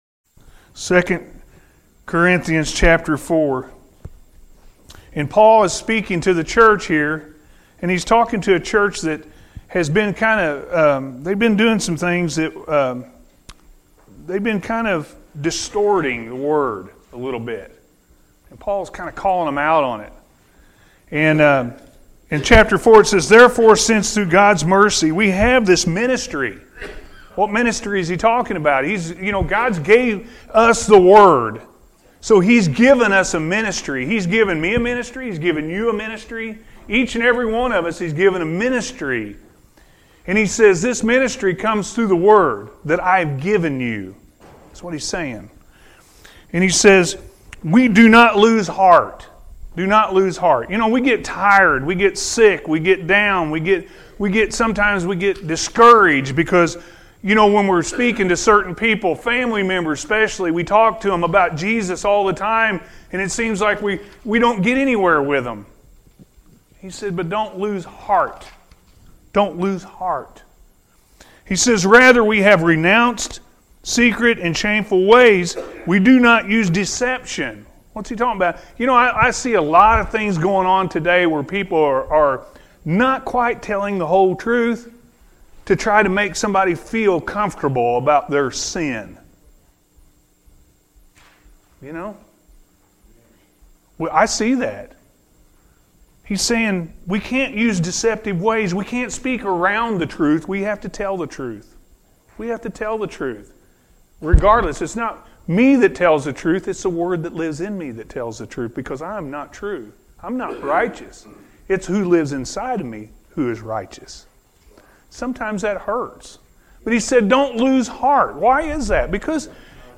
We Have A Ministry-A.M. Service